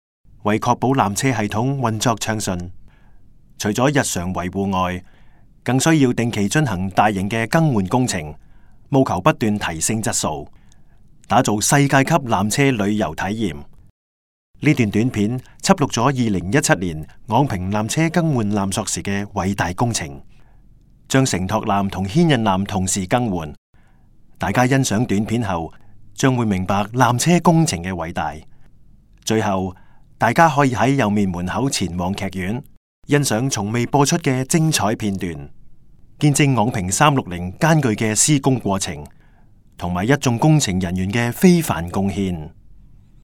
纜車探知館語音導賞 (廣東話)